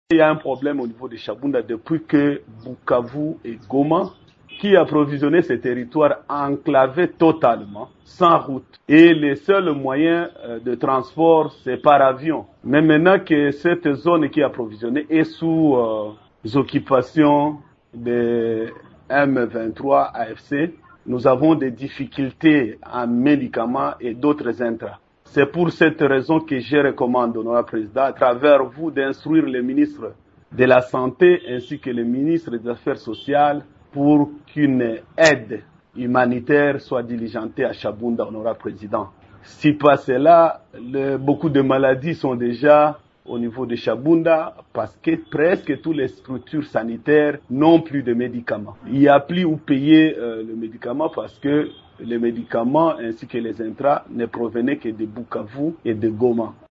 Lors de son intervention à l’Assemblée nationale, vendredi 16 mai, il a attribué cette crise à la rupture du pont aérien reliant Goma et Bukavu à cette région, conséquence directe de la guerre du M23.